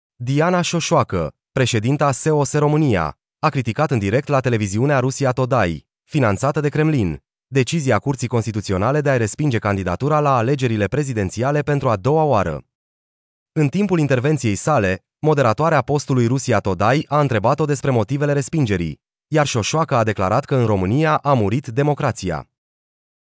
Șoșoacă, show în direct la Russia Today: „Sunt putinistă, pentru că iubesc Rusia. Nu sunt pregătiți pentru mine”
Diana Șoșoacă, președinta S.O.S. România, a criticat în direct la televiziunea Russia Today, finanțată de Kremlin, decizia Curții Constituționale de a-i respinge candidatura la alegerile prezidențiale pentru a doua oară.
În timpul intervenției sale, moderatoarea postului Russia Today a întrebat-o despre motivele respingerii, iar Șoșoacă a declarat că în „România a murit democrația”: